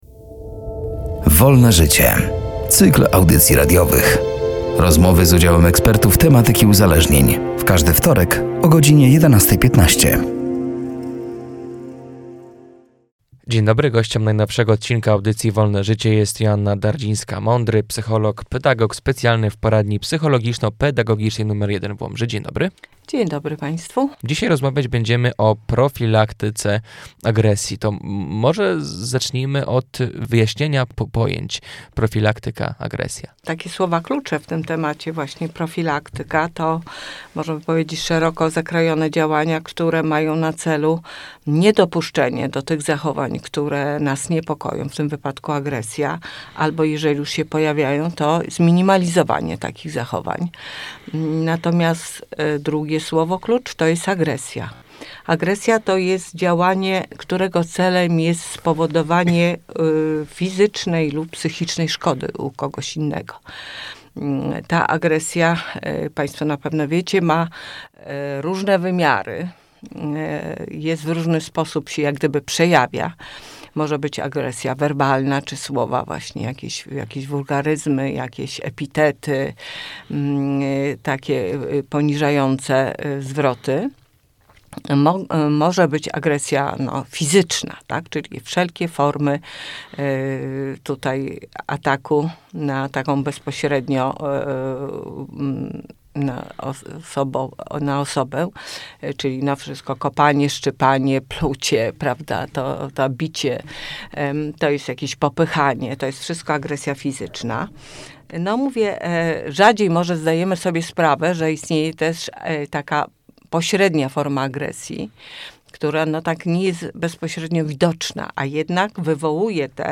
„Wolne życie” to cykl audycji radiowych. Rozmowy z udziałem ekspertów z obszaru psychologii i uzależnień.